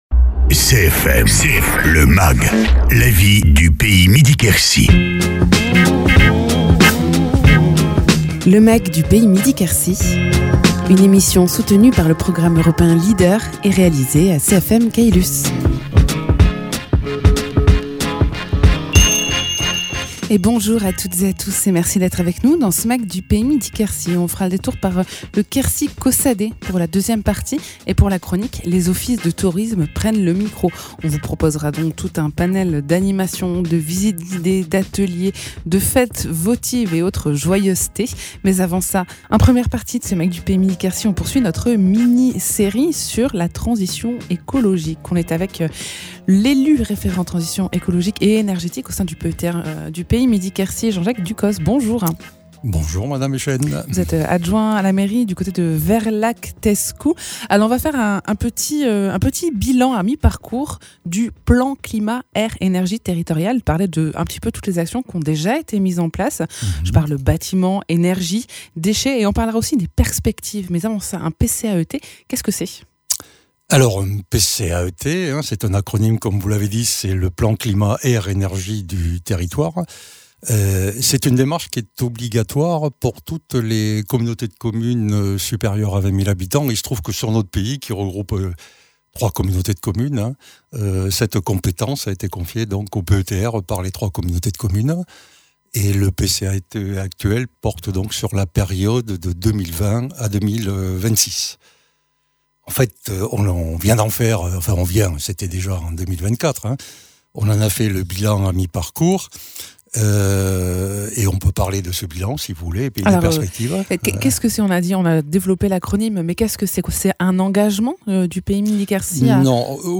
Invité(s) : Jean-Jacques Ducos, élu référent transition énergetique au Pays Midi-Quercy